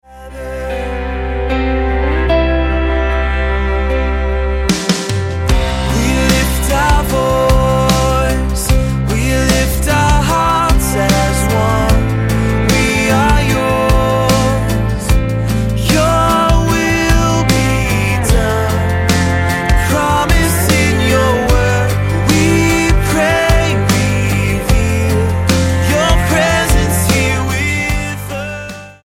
Husband and wife duo
Style: Pop Approach: Praise & Worship